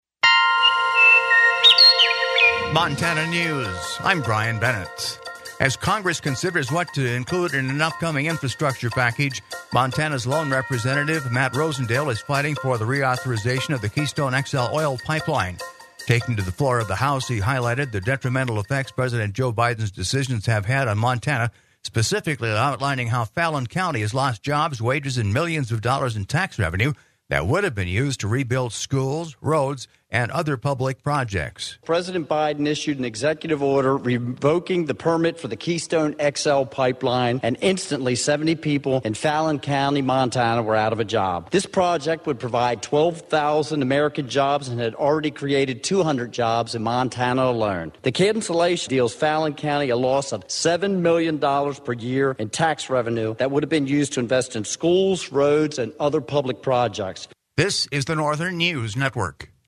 As Congress considers what to include in an upcoming infrastructure package Montana’s lone Representative Matt Rosendale is fighting for the reauthorization of the Keystone XL oil pipeline. Taking to the floor of the House he highlighted the detrimental effects President Joe Biden’s decisions have had on Montana specifically outlining how Fallon County has lost jobs wages and millions of dollars in tax revenue that would have been used to rebuild schools roads and other public projects.